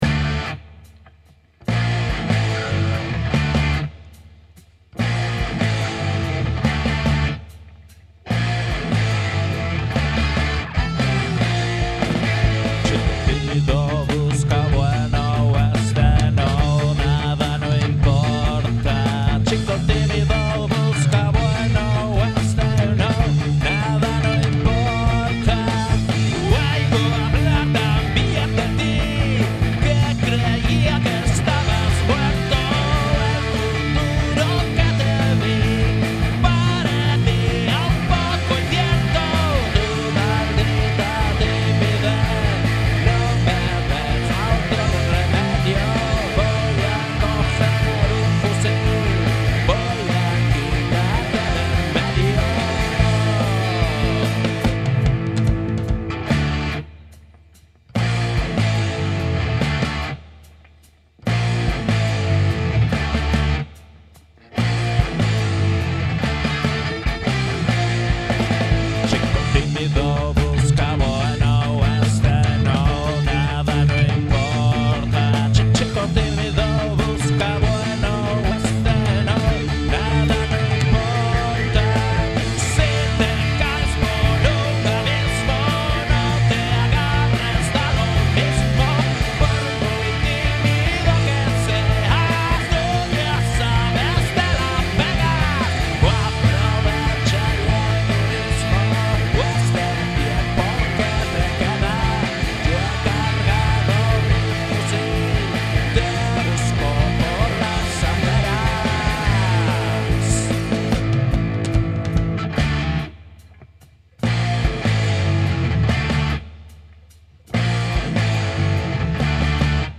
BAJO
BATERIA
GUITARRA
GUITARRA Y VOZ